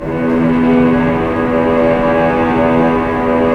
Index of /90_sSampleCDs/Roland LCDP08 Symphony Orchestra/STR_Vcs Bow FX/STR_Vcs Sul Pont